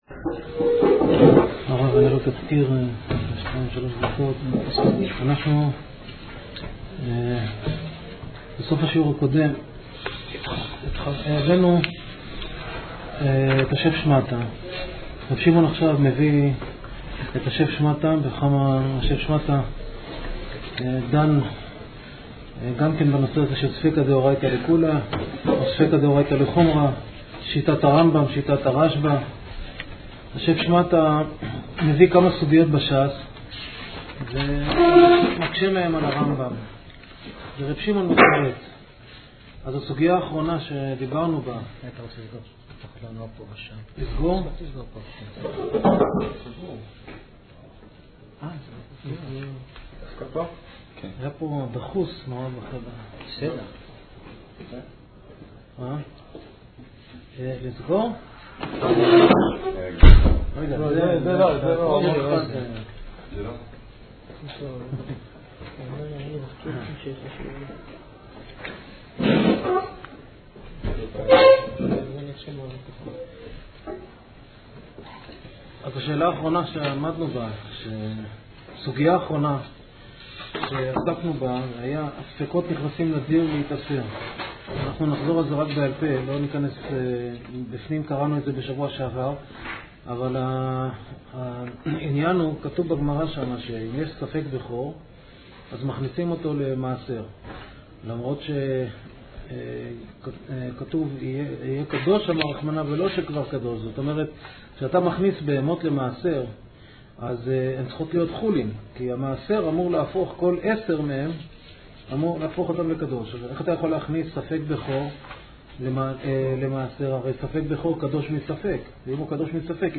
גמרא